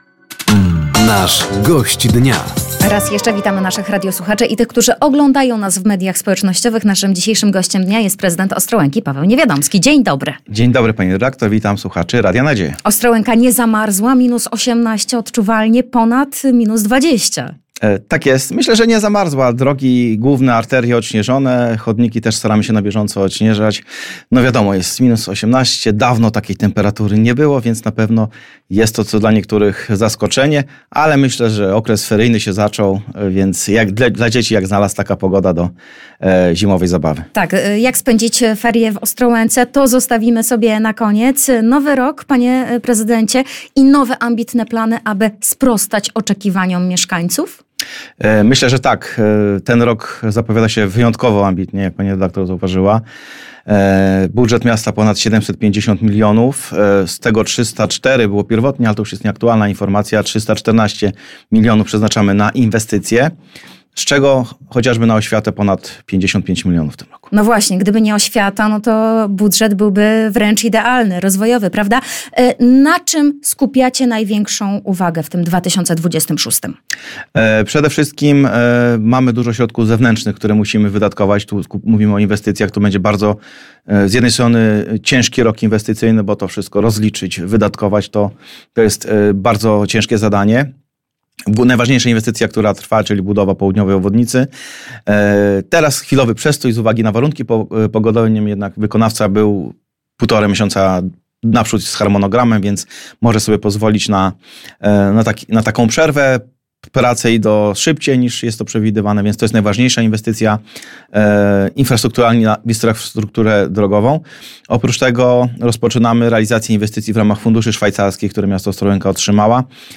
Zima w mieście, sprawy związane z bieżącym funkcjonowaniem oraz plany na przyszłość były tematem rozmów z Gościem Dnia. Studio Radia Nadzieja odwiedził Paweł Niewiadomski – Prezydent Ostrołęki.